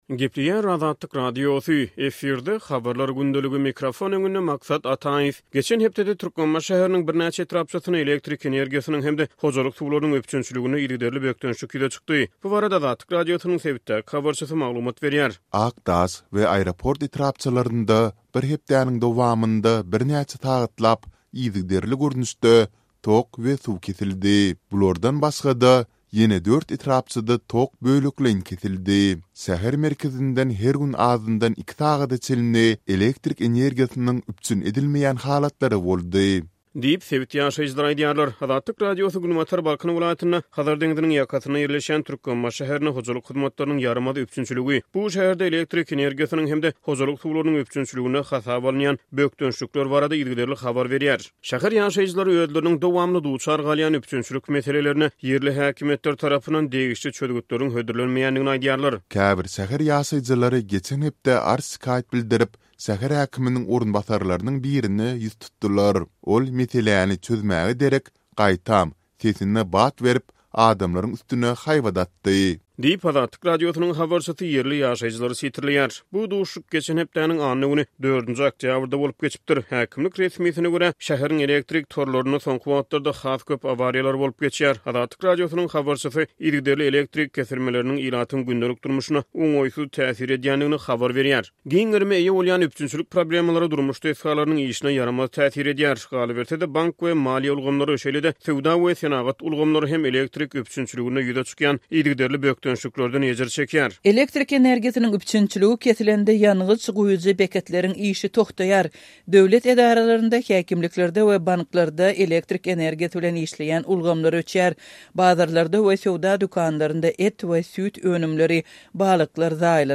Geçen hepdede Türkmenbaşy şäheriniň birnäçe etrapçasynda elektrik energiýasynyň hem-de hojalyk suwlarynyň üpjünçiliginde yzygiderli bökdençlik ýüze çykdy, häkimiýetler dessin çözgüt hödürläp bilmedi. Bu barada Azatlyk Radiosynyň habarçysy sebitden maglumat berýär.